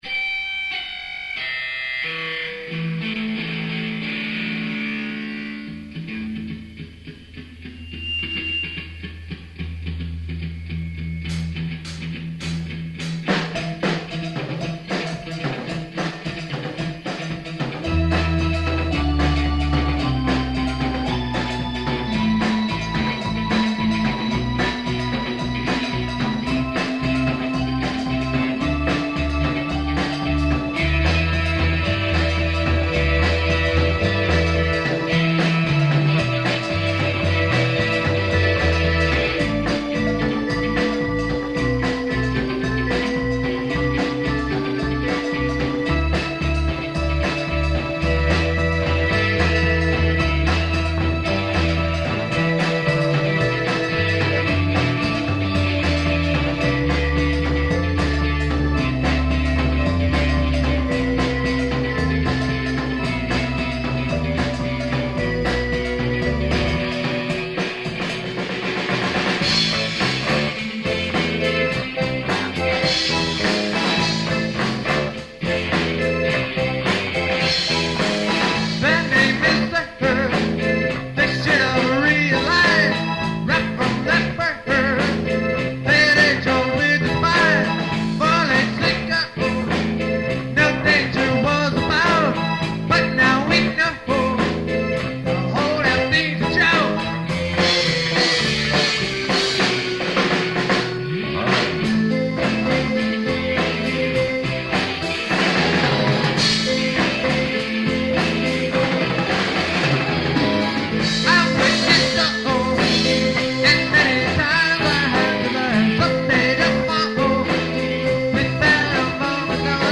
Keep in mind that these are from 20-year old cassette tapes, so sound quailty is definitely an issue. We apologize in advance for all the mistakes and questionable singing, but this was a practice session after all.
The band played some covers and mostly original material in the style of ska and reggae.